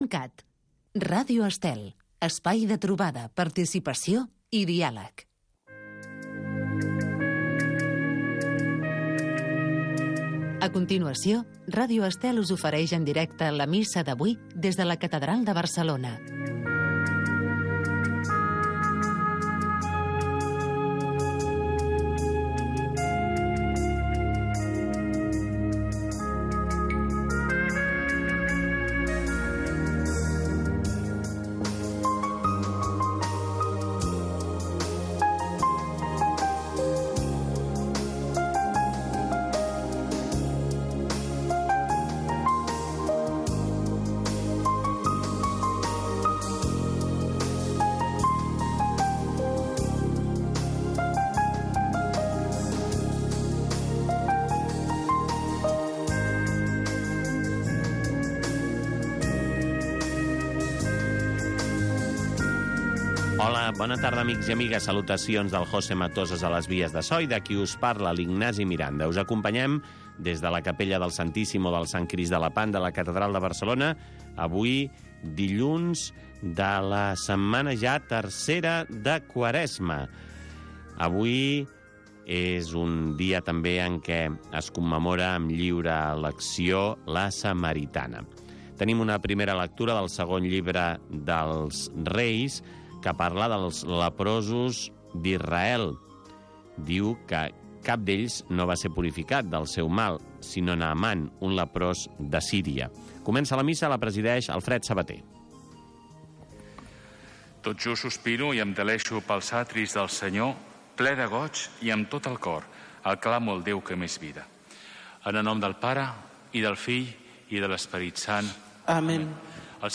La Missa de cada dia. Cada dia pots seguir la Missa en directe amb Ràdio Estel.